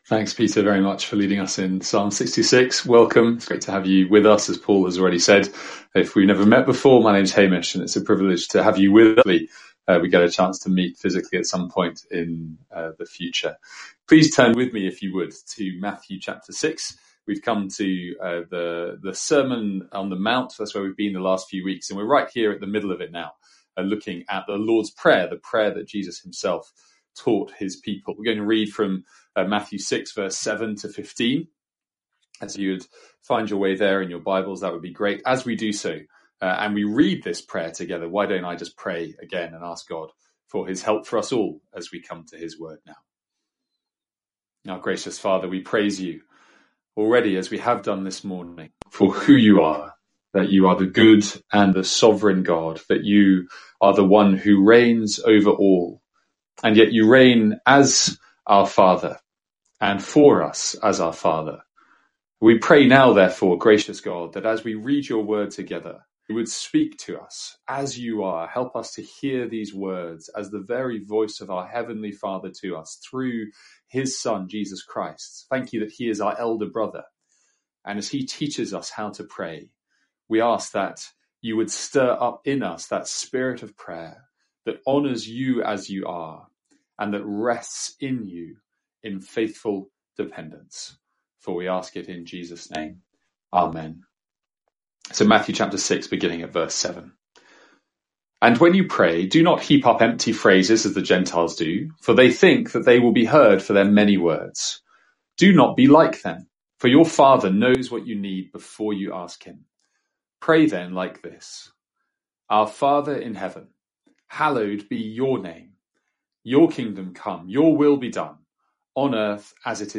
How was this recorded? From our morning series on the Sermon of the Mount.